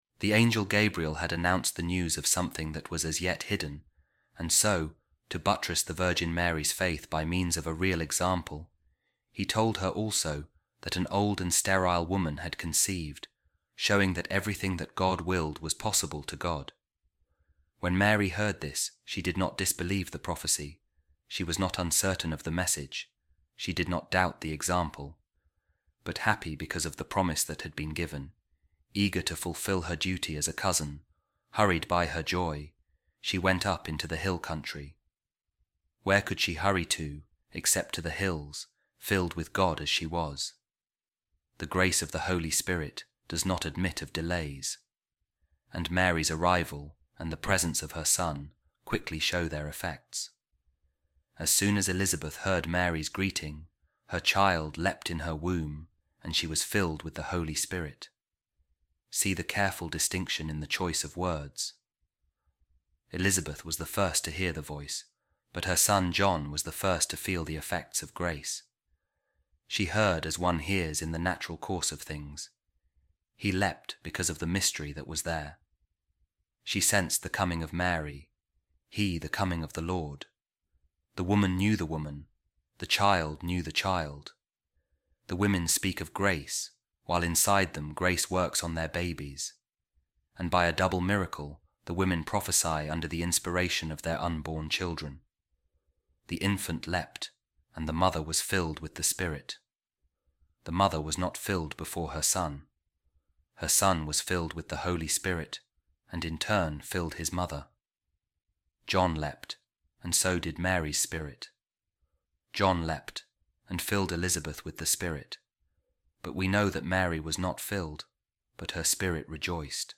Office Of Readings | Advent December 21st | A Reading From The Commentary Of Saint Ambrose On Saint Luke’s Gospel | The Visitation Of The Blessed Virgin Mary